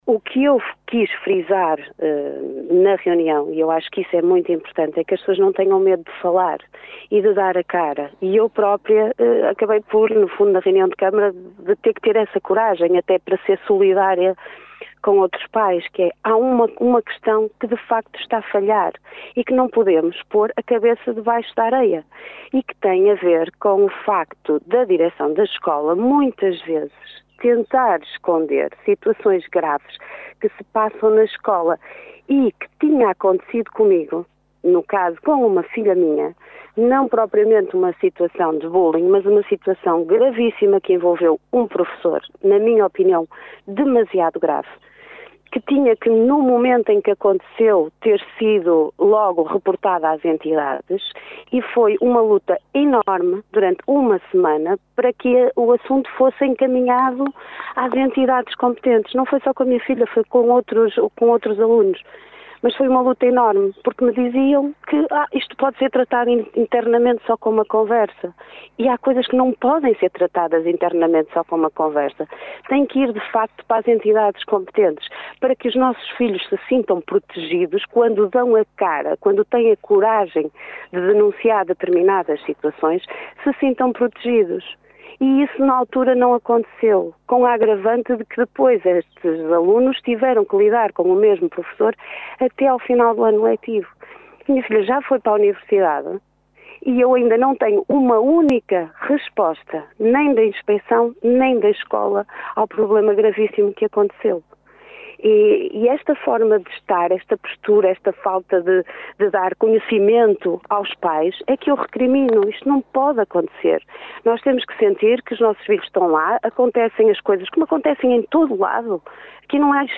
Em entrevista hoje ao Jornal C – O Caminhense, Liliana Silva explicou a importância de reportar estes problemas assim que eles acontecem e de envolver as entidades competentes e não abafar e tentar resolver os problemas apenas internamente.